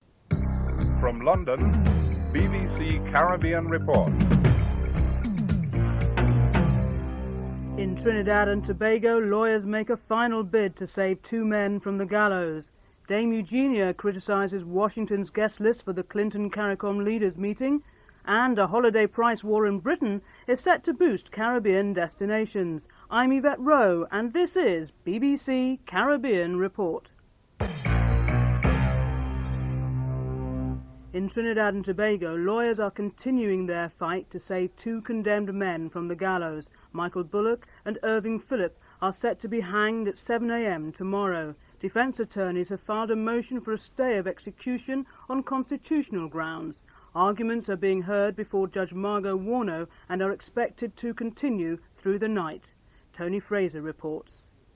1. Headlines (00:00-00:37)
Interview with Eugenia Charles, Prime Minister of Dominica (07:13-09:01)